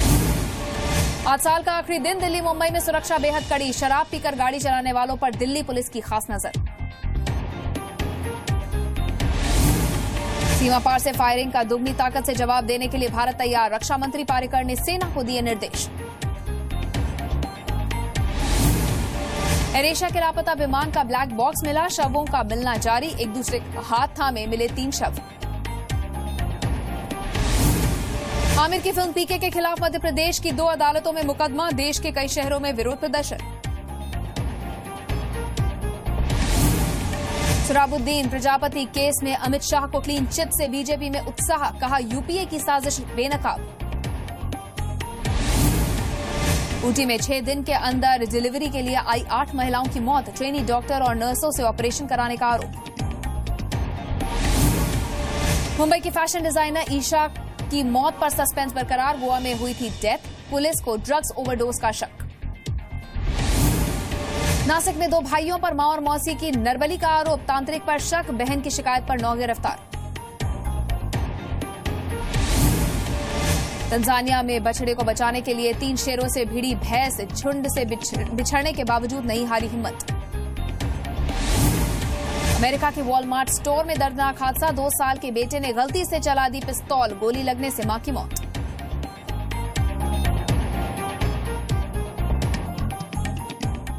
Top headlines of the day